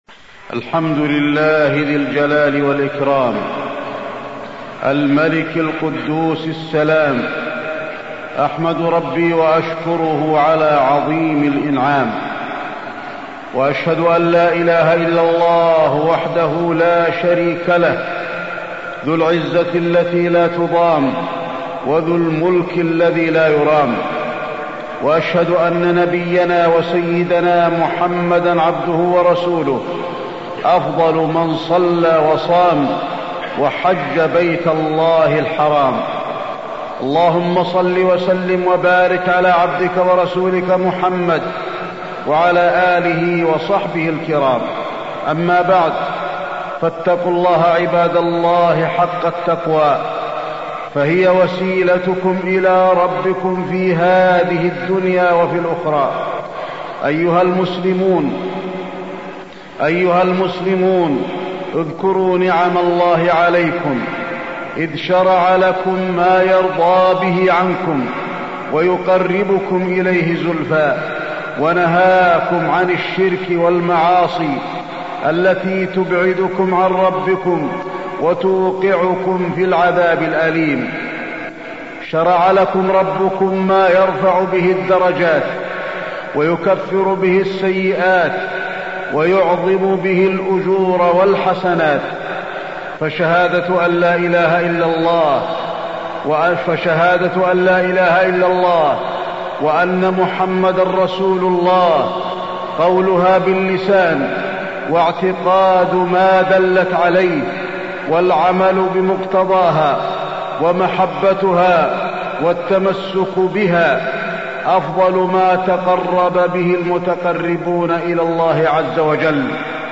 تاريخ النشر ١ رمضان ١٤٢٥ هـ المكان: المسجد النبوي الشيخ: فضيلة الشيخ د. علي بن عبدالرحمن الحذيفي فضيلة الشيخ د. علي بن عبدالرحمن الحذيفي شهادة أن لا إله إلا الله